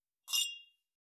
268,ショットグラス乾杯,乾杯,アルコール,バー,お洒落,モダン,カクテルグラス,ショットグラス,おちょこ,テキーラ,シャンパングラス,カチン,チン,カン,ゴクゴク,
コップ